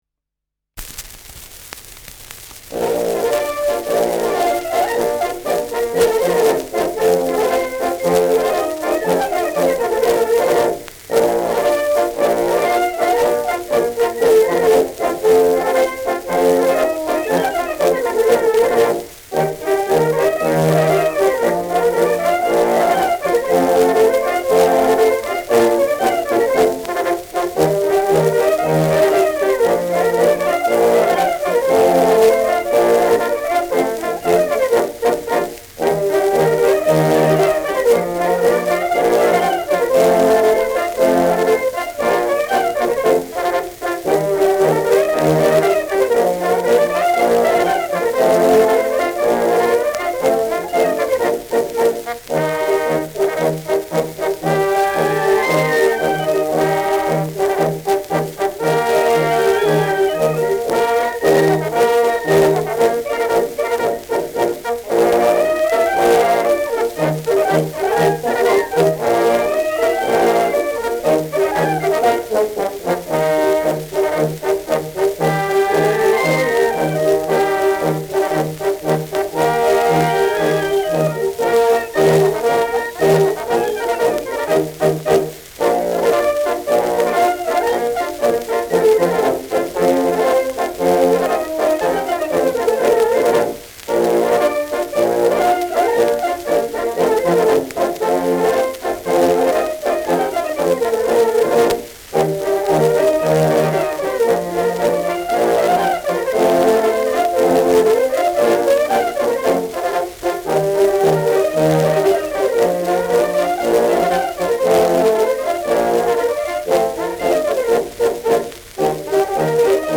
Schellackplatte
leichtes Rauschen : leichtes Knistern : leichtes „Schnarren“ : vereinzeltes Knacken
Militärmusik des k.b. 14. Infanterie-Regiments, Nürnberg (Interpretation)
Das Musikkorps spielt bei der Schallplattenaufnahme nicht in voller Stärke, sondern in einer kleinen Besetzung, vermutlich bestehend aus Piccolo-Flöte, 2 Klarinetten, 2 Trompeten, 2 bis 3 Begleit- bzw. Nachschlaginstrumenten und Tuba.